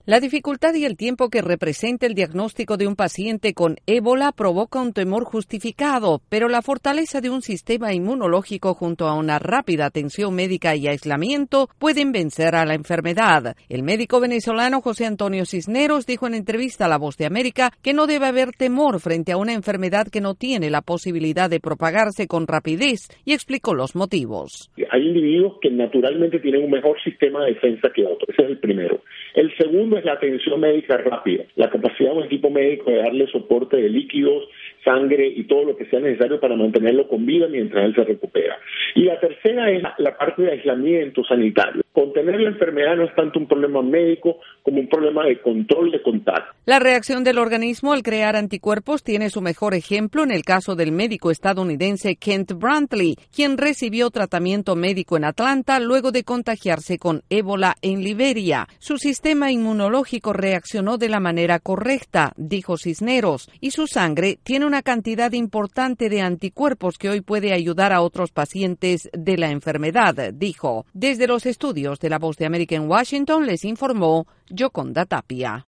Desde la Voz de América en Washington DC